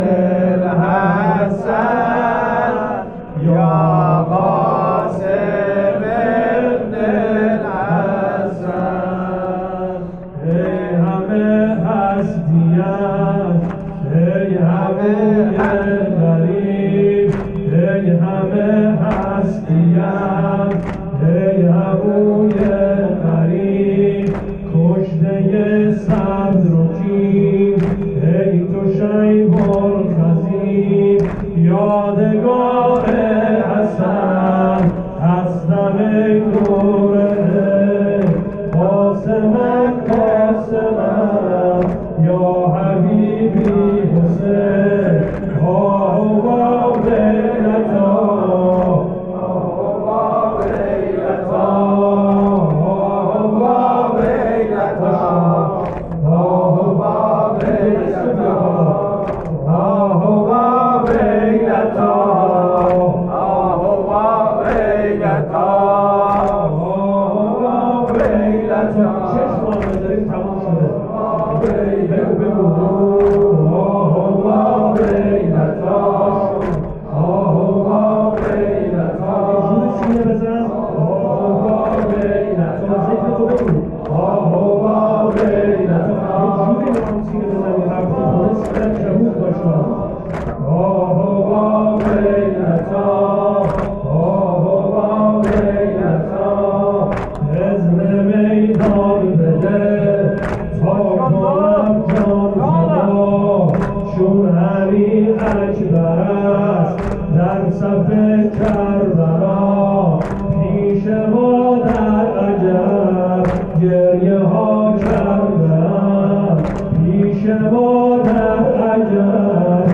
مداحی
شب ششم محرم ۱۴۰۱